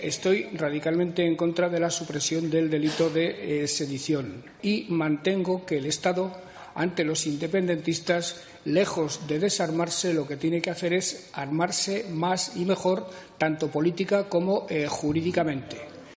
El presidente del Gobierno de Aragón, Javier Lambán, ha afirmado este viernes, en la sesión plenaria de las Cortes, que "la polarización creciente" de España es "preocupante" y que el PP es "responsable como el que más".